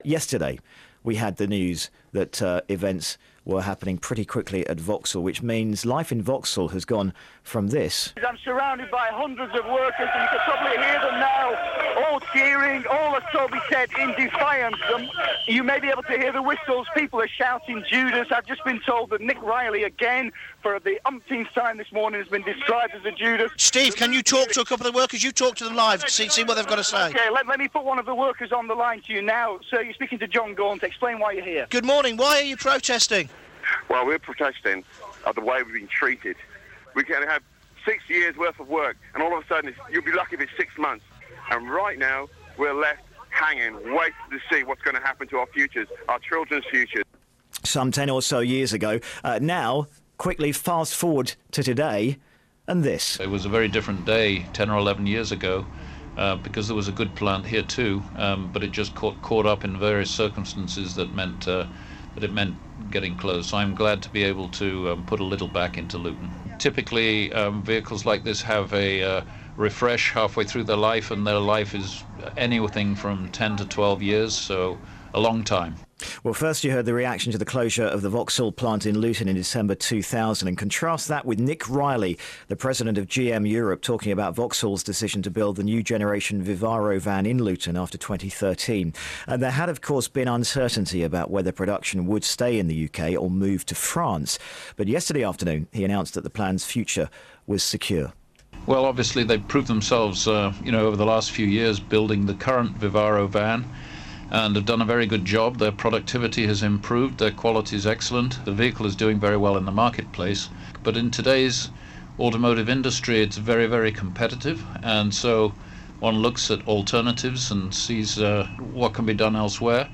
Headliner Embed Embed code See more options Share Facebook X Subscribe Share Facebook X Subscribe Next The Luton plant will make the new version of Vauxhall's Vivaro van. These people in Luton told us their reaction...